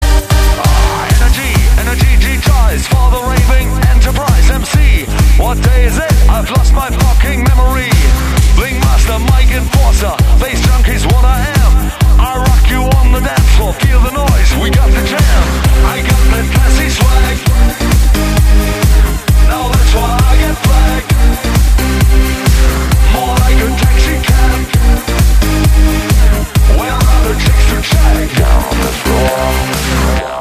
• Качество: 320, Stereo
мужской вокал
Electronic
EDM
techno